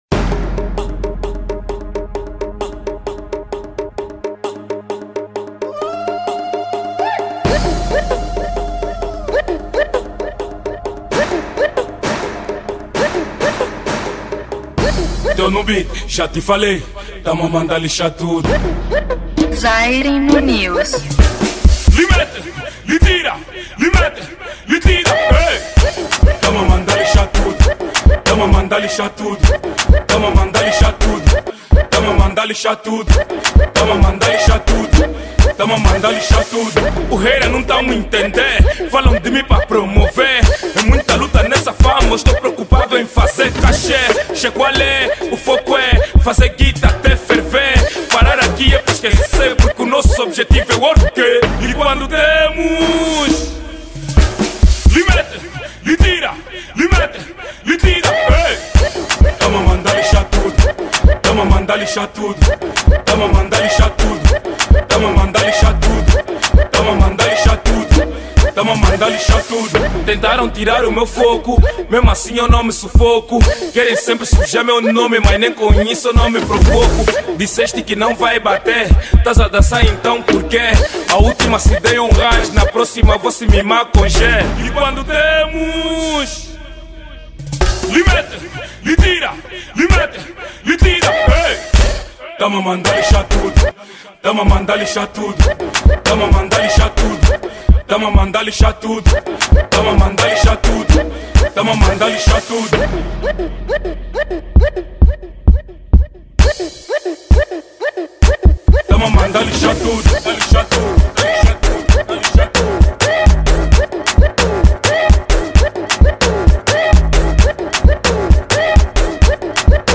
Afrohouse